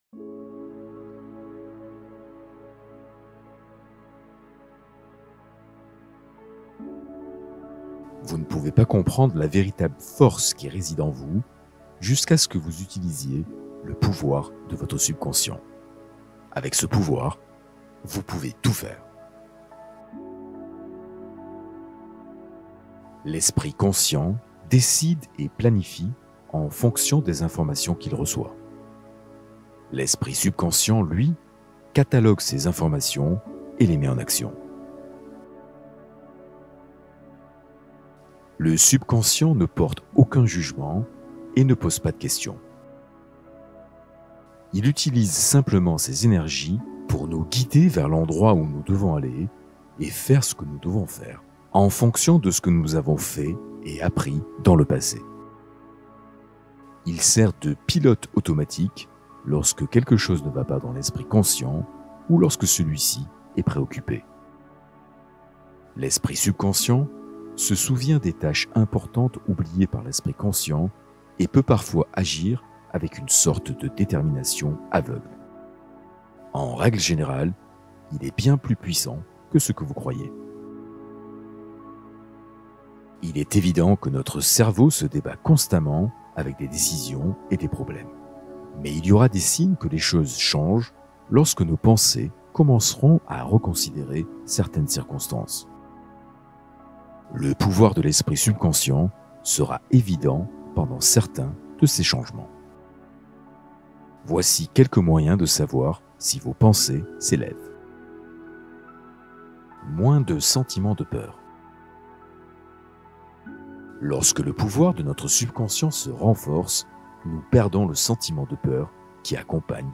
Méditation guidée pour réparer ton ADN et activer la guérison